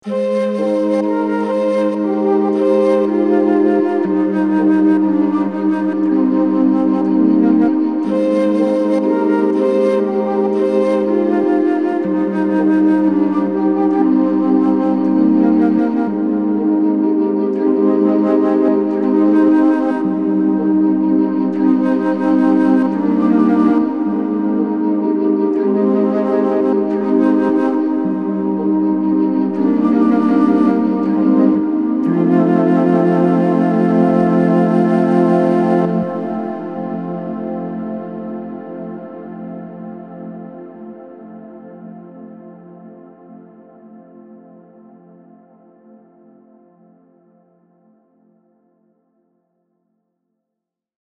Single patch demo